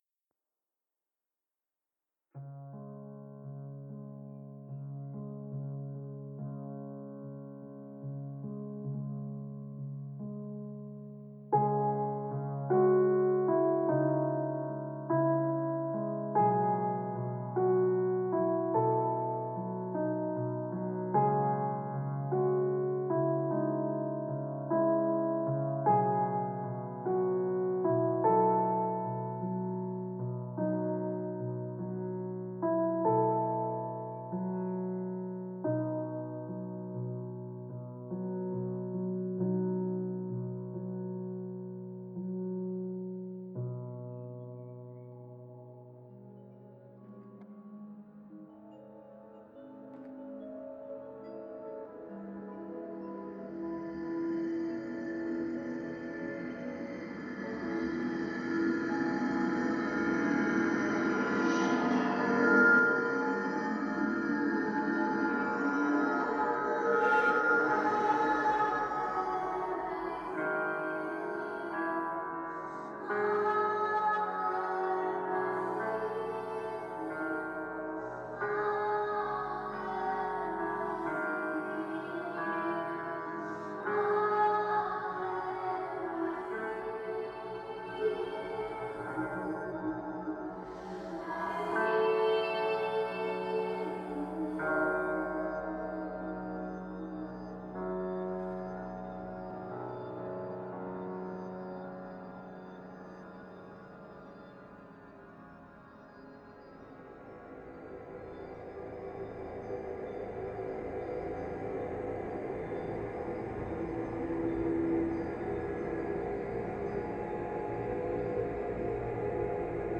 Strumenti: Piano e Synth